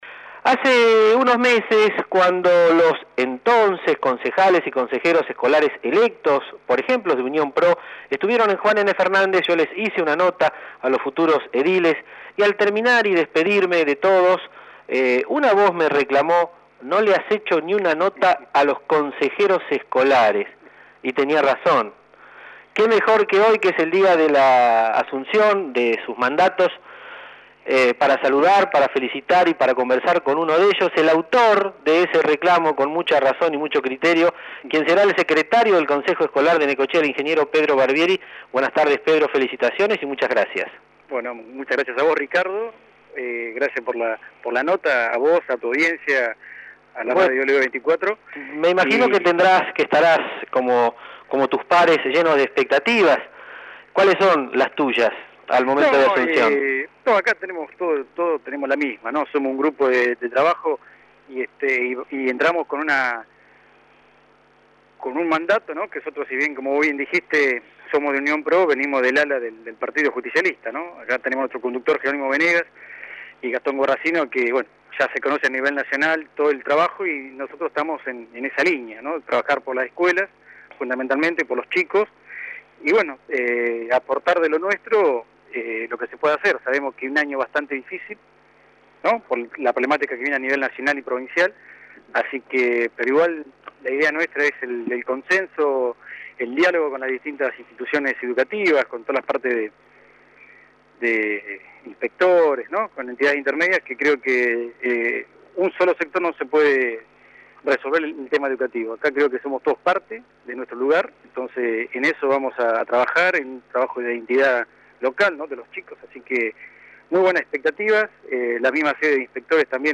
En diálogo exclusivo con LU 24 y JNFNet, Barbieri aseguró que son muchas las expectativas al asumir este cargo.
Escuchar audio del Ing. Pedro Barbieri